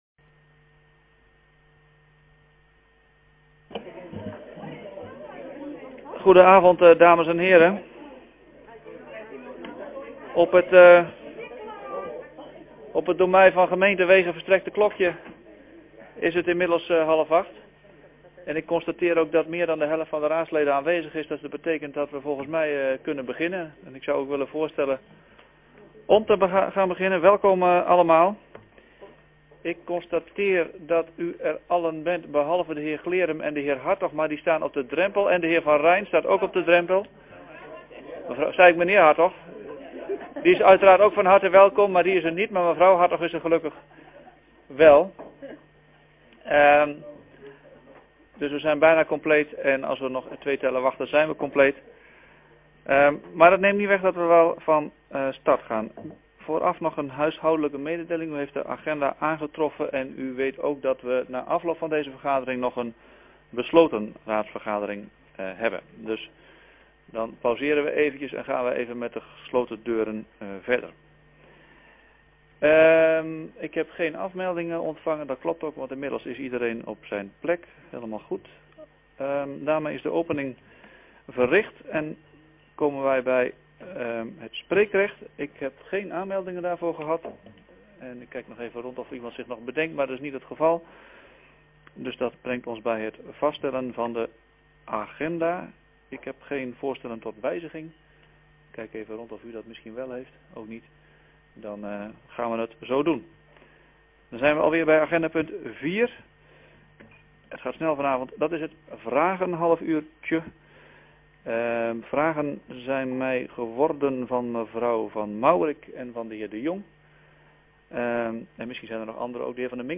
Agenda Molenlanden - Raad Giessenlanden donderdag 22 januari 2015 20:00 - iBabs Publieksportaal
Hoornaar, gemeentehuis - raadzaal